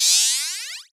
TM88 - FX (9).wav